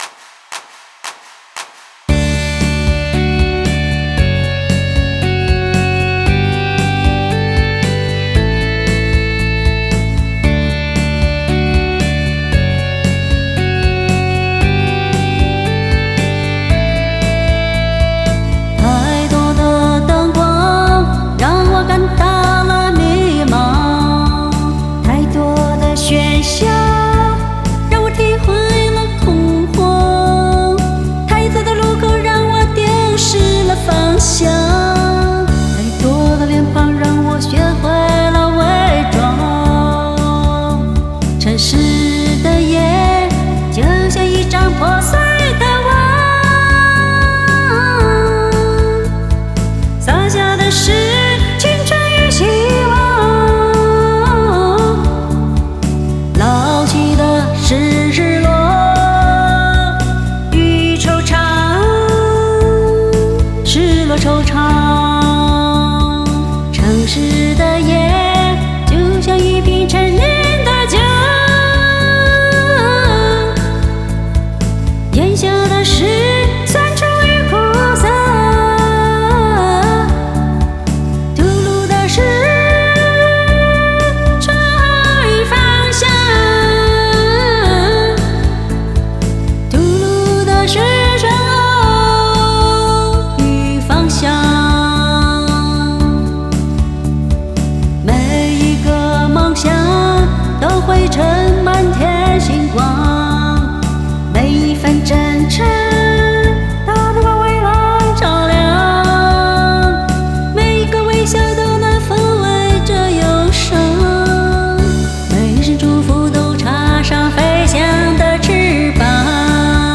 （女）